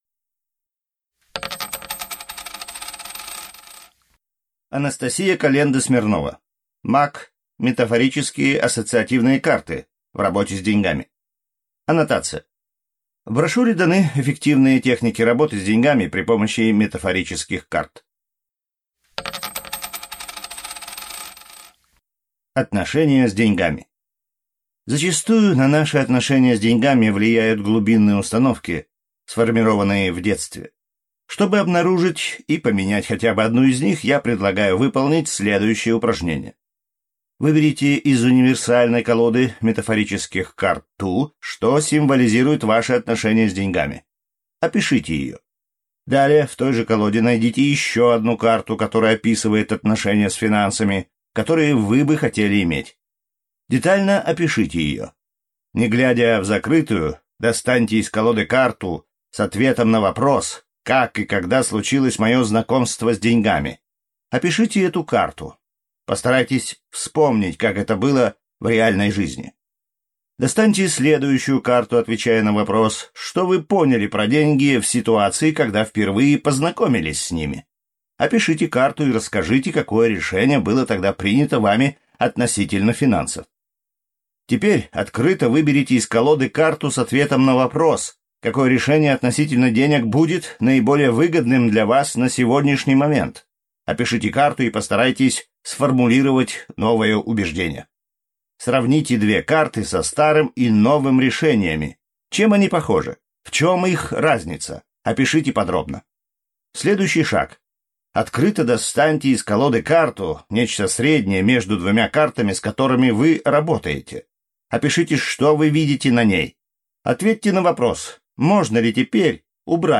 Аудиокнига МАК (метафорические ассоциативные карты) в работе с деньгами | Библиотека аудиокниг